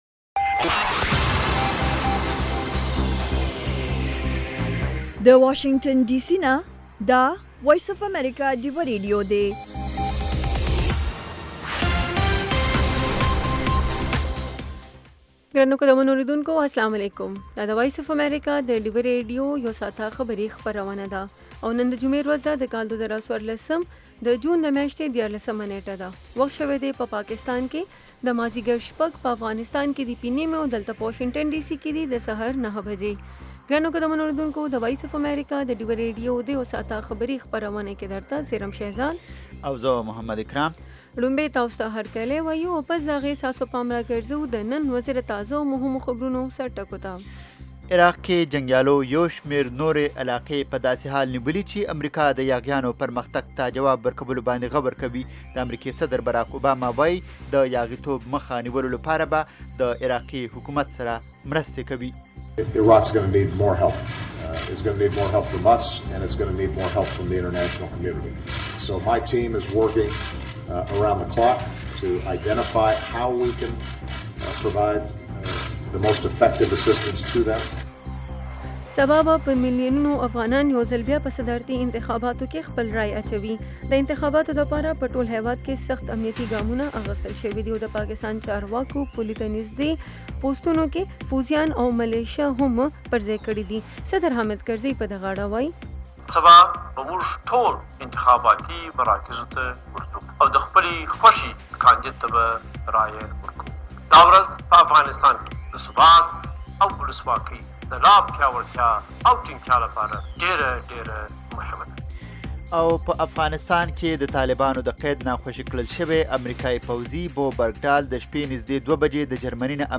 خبرونه - 1300